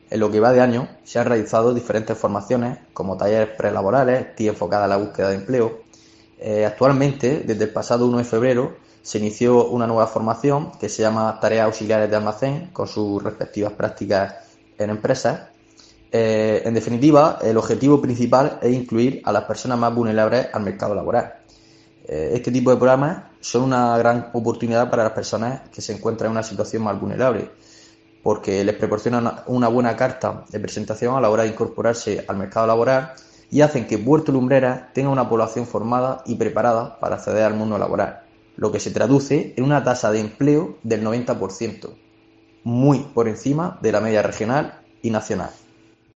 José Manuel Sánchez, concejal Ayto Puerto Lumbreras